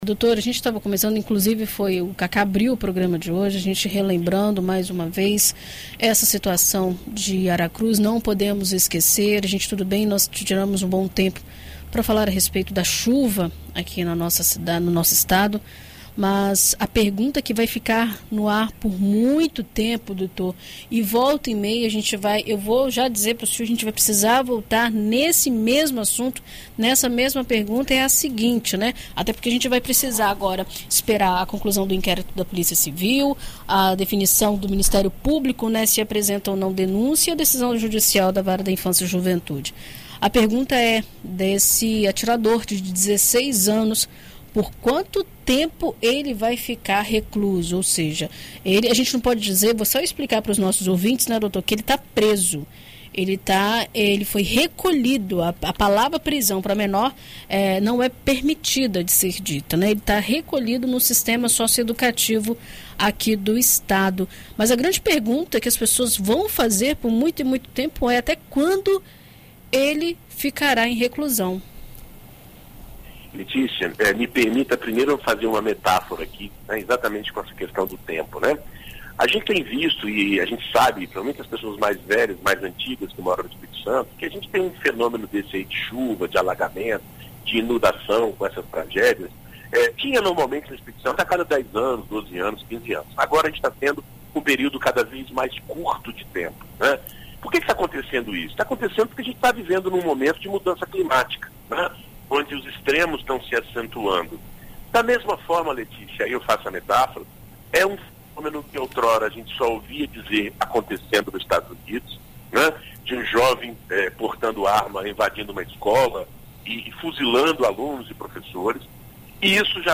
Na coluna Direito para Todos desta quarta-feira (30), na BandNews FM Espírito Santo